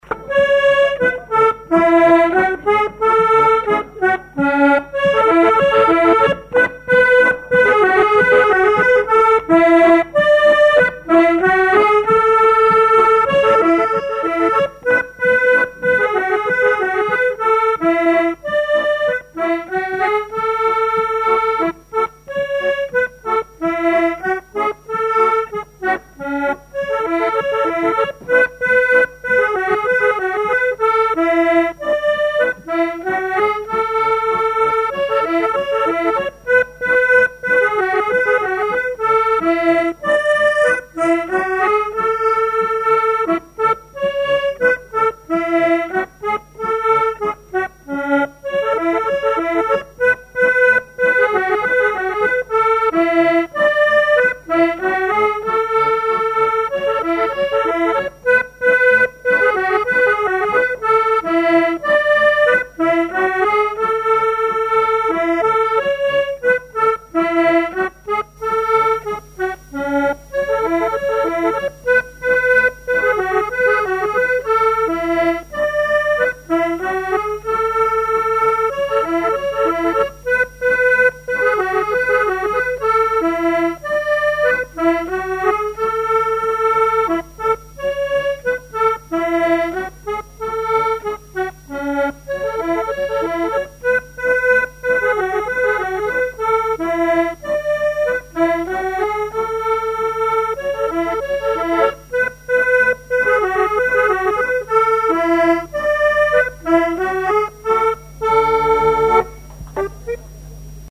danse : spirou
collectif de musiciens pour une animation à Sigournais
Pièce musicale inédite